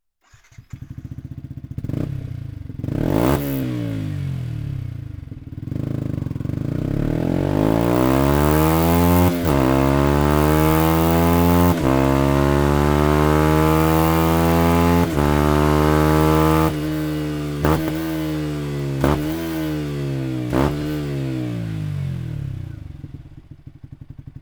Die Systeme sind leichter als die Serien-Auspuffanlagen und überzeugen mit herausragender Qualität sowie einer erhöhten Motorleistung kombiniert mit purem Racing-Sound.
Sound Akrapovic Racing Line Komplettanlage